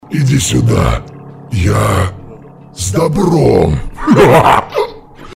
Звуки дьявола